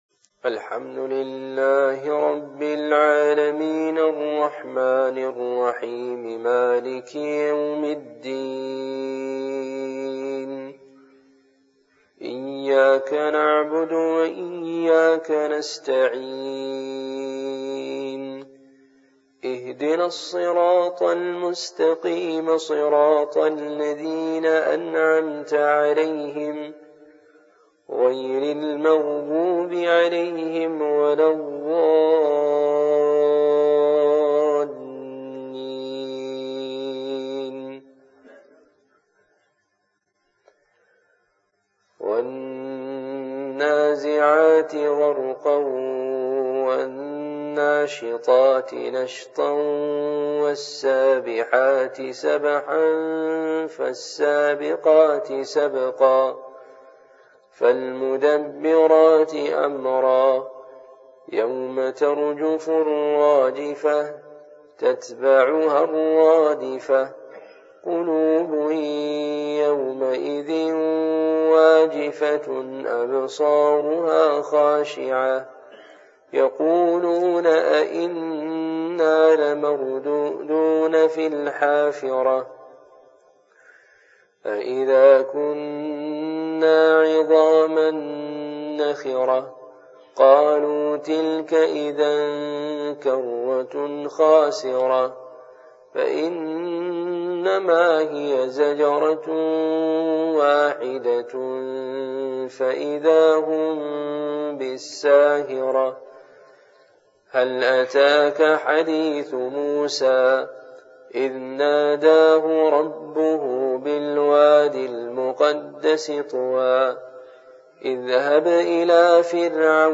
Fajr, Qiraat And Dua